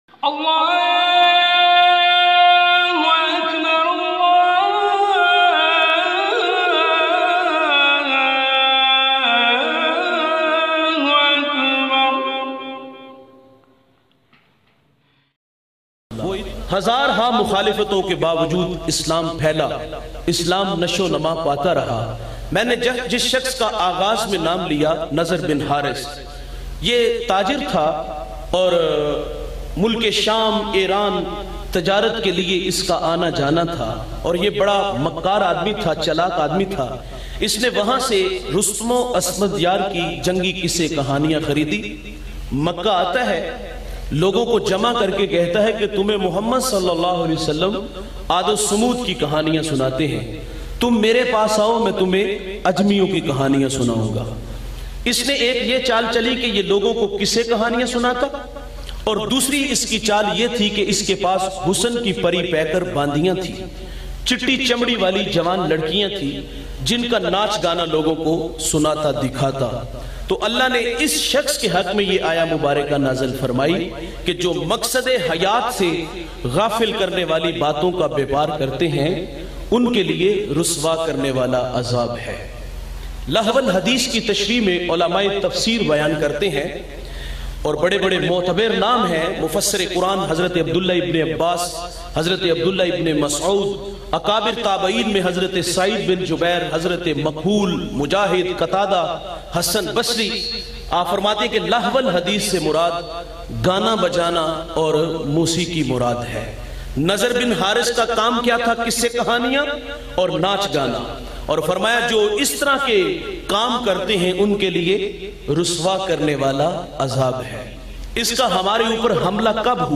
Music Sunana Bhut Bara Gunah Ha Bayan mp3